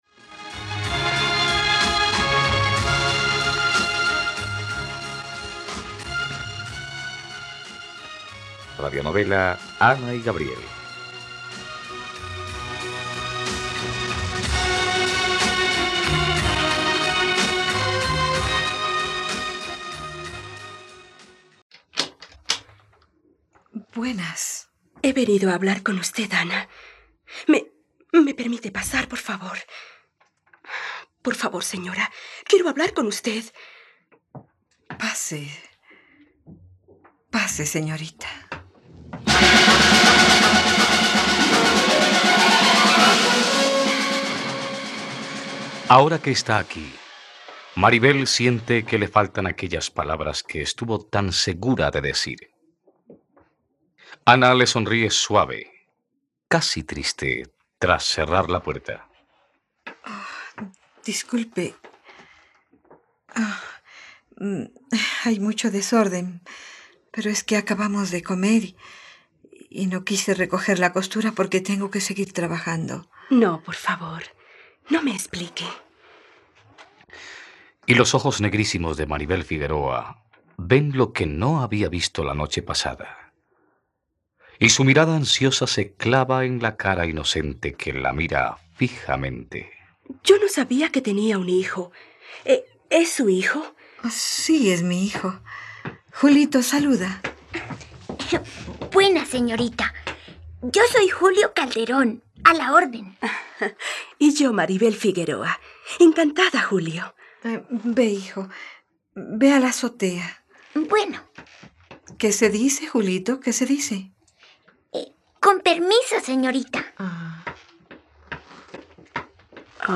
..Radionovela. Escucha ahora el capítulo 69 de la historia de amor de Ana y Gabriel en la plataforma de streaming de los colombianos: RTVCPlay.